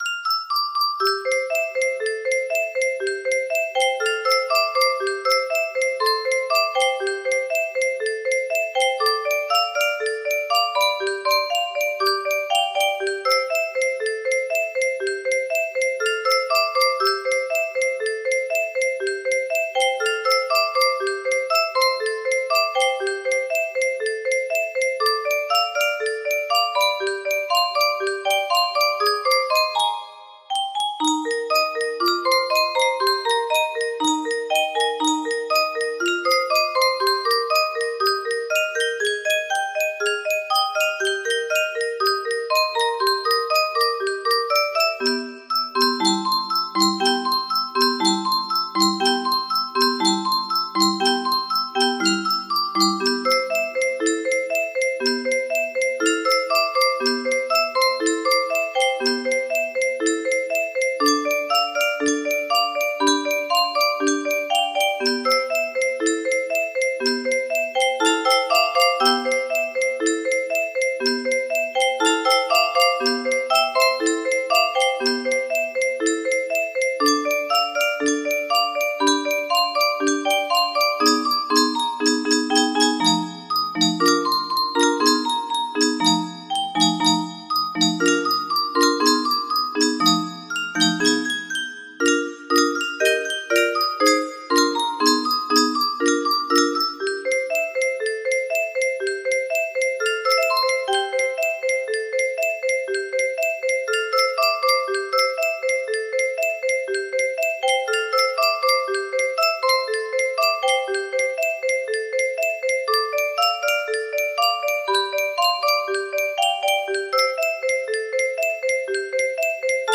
Unknown Artist - Untitled music box melody